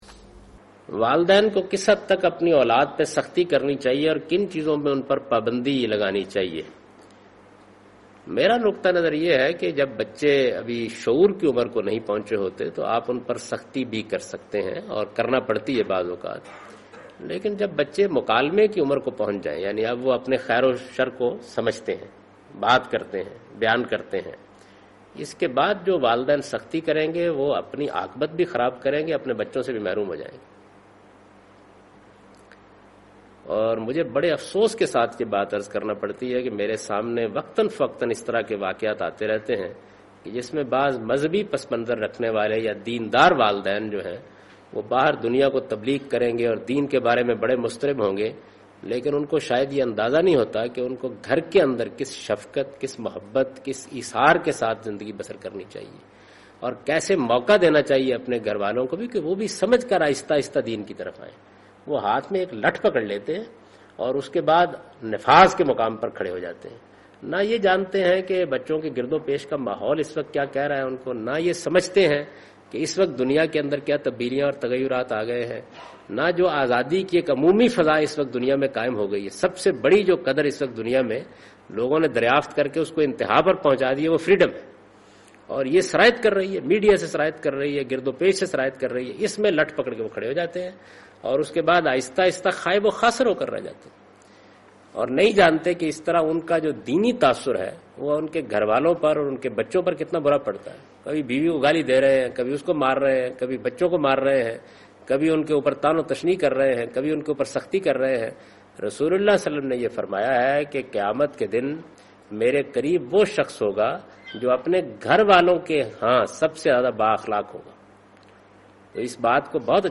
Javed Ahmad Ghamidi responds to the question 'Can parents coerce or pressurized their children and to what limit'?